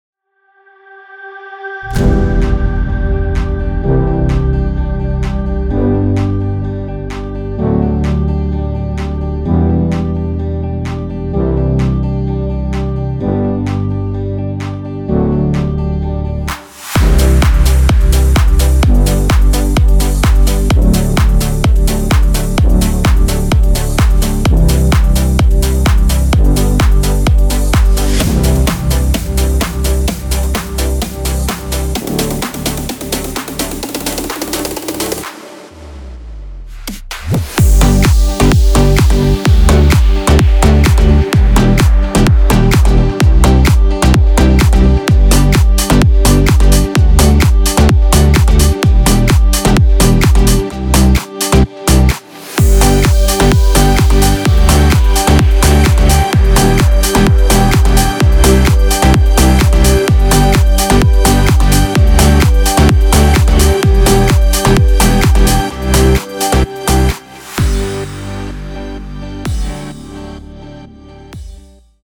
Popschlagersong im aktuellen Style.
Hier kannst du kurz ins Playback reinhören.
BPM – 128
Tonart – G-major